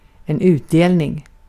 Uttal
Synonymer dividend Uttal : IPA: [ˈʉːˌteːl.nɪŋ] Ordet hittades på dessa språk: svenska Översättning 1. kâr payı Artikel: en .